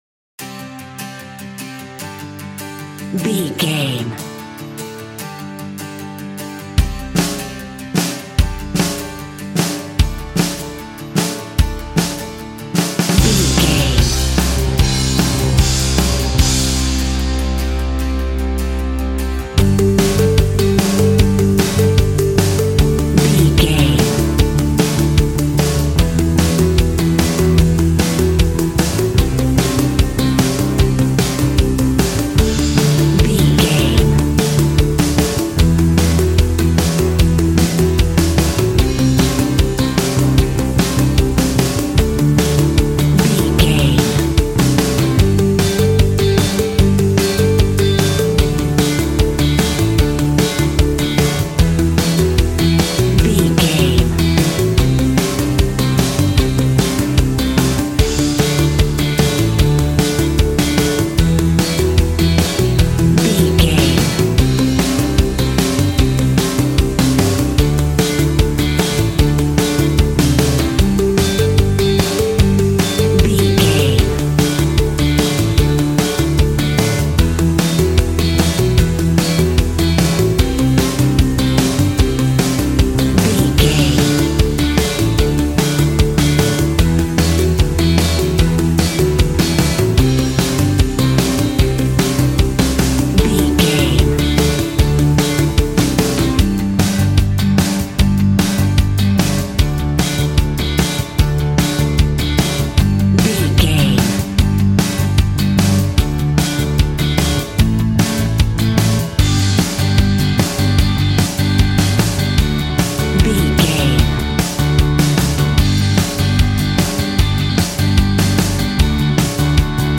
Ionian/Major
D
groovy
powerful
organ
drums
bass guitar
electric guitar
piano